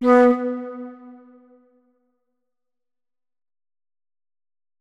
328d67128d Divergent / mods / Hideout Furniture / gamedata / sounds / interface / keyboard / flute / notes-35.ogg 42 KiB (Stored with Git LFS) Raw History